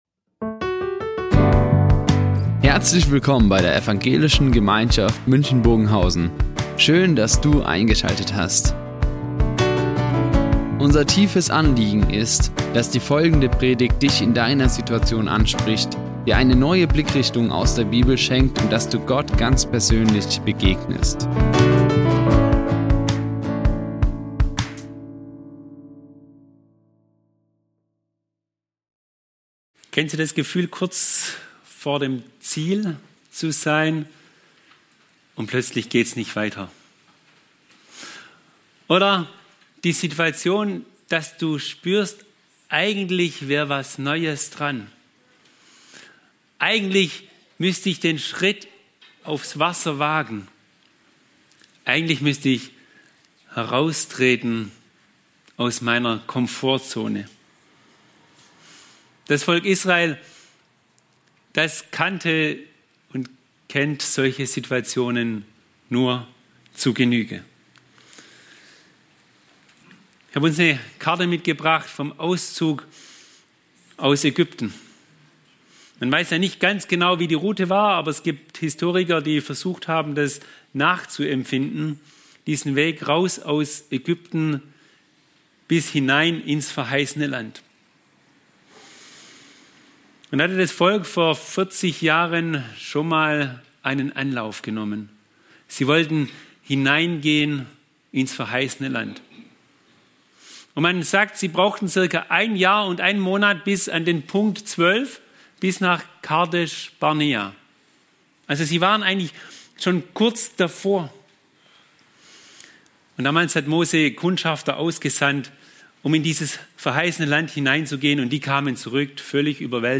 Sei entschlossen | Predigt Josua 3 ~ Ev.
Die Aufzeichnung erfolgte im Rahmen eines Livestreams.